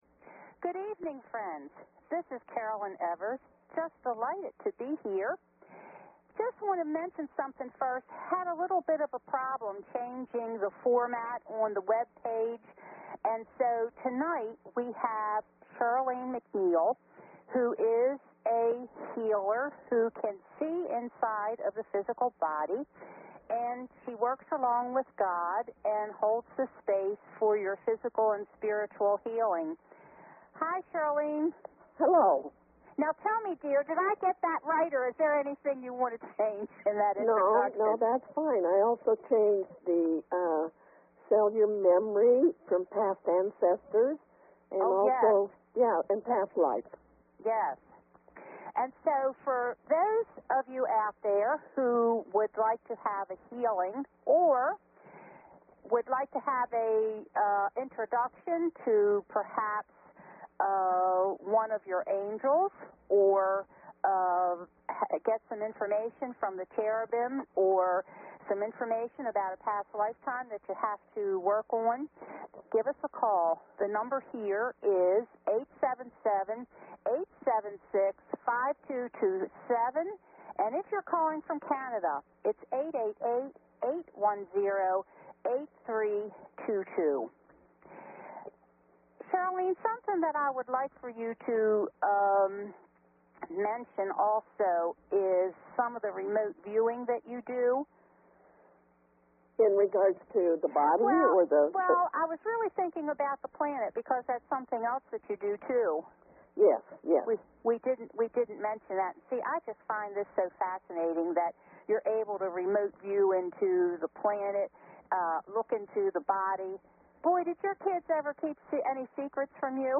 Talk Show Episode, Audio Podcast, Cosmic_Connections and Courtesy of BBS Radio on , show guests , about , categorized as